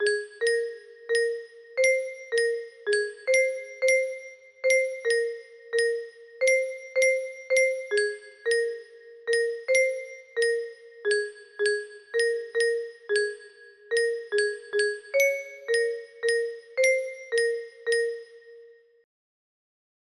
8543r678 music box melody